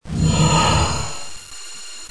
Молнии: